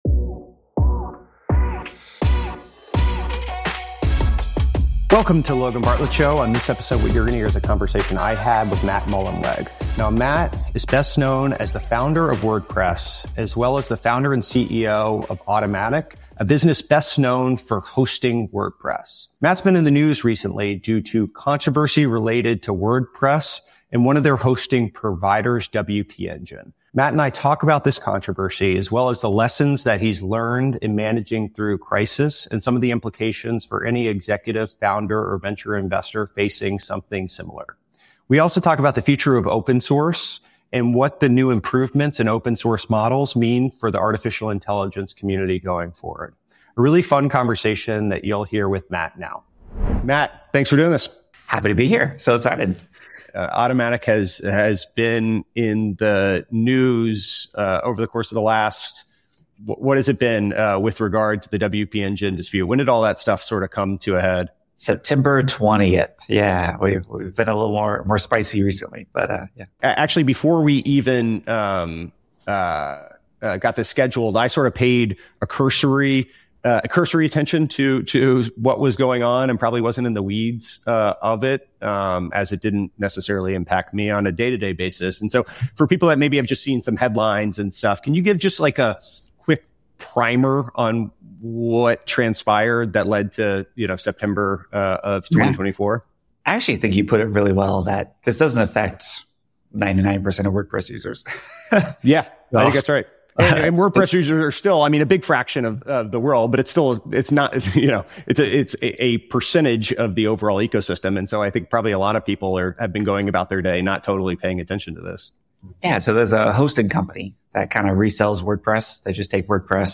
In our conversation, he shared lessons from the controversy and managing through crisis, as well as this thoughts on the future of open source AI and more.